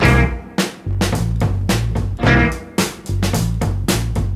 • 109 Bpm Drum Beat C Key.wav
Free breakbeat - kick tuned to the C note. Loudest frequency: 1298Hz
109-bpm-drum-beat-c-key-qVN.wav